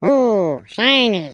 Descarga de Sonidos mp3 Gratis: voz dibujos animados.